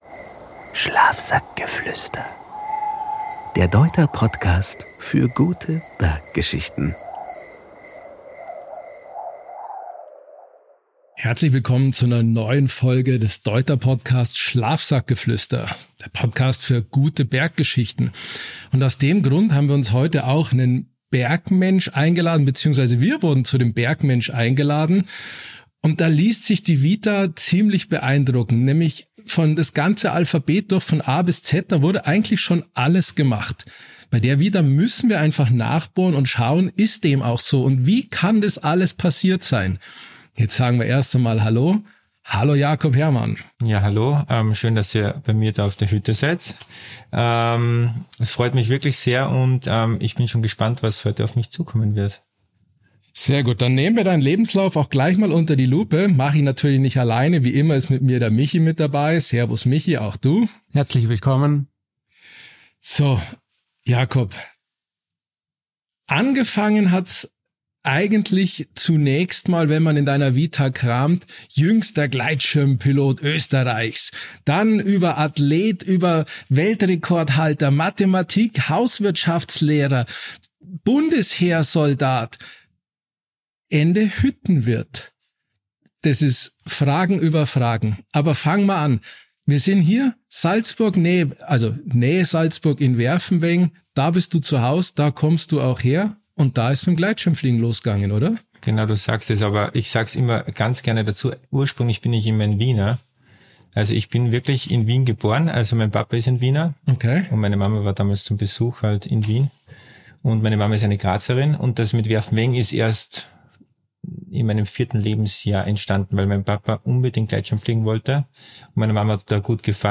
Es geht um mentale Stärke, Grenzerfahrungen, Verletzungen, Ernährung, Motivation – und um die bewusste Entscheidung, einen Gang zurückzuschalten. Ein ehrliches Gespräch über Erfolg und Zweifel, über Extremsport und Entschleunigung.